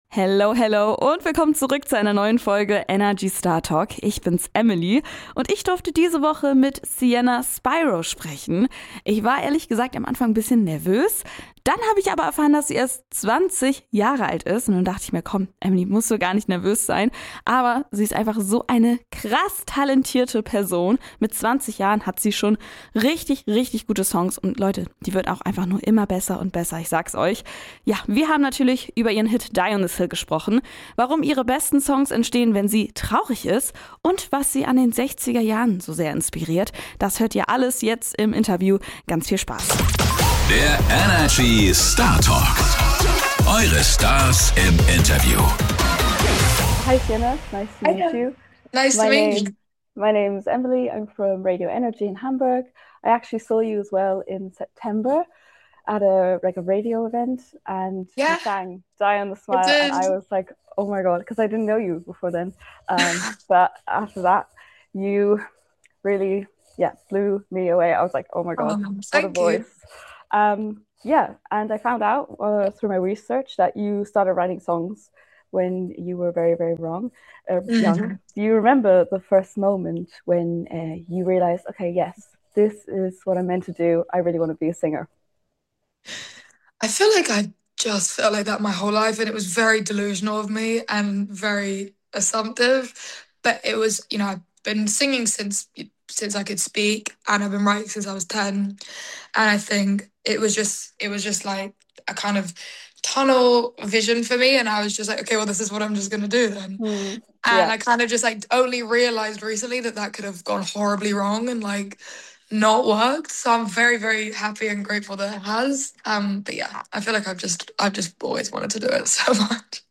Außerdem geht’s um ihre große Inspiration aus den 60er Jahren, den besonderen Vibe dieser Zeit und warum der bis heute ihren Sound prägt. Ein ehrliches Gespräch über Gefühle, Musik und die Magie von Melancholie.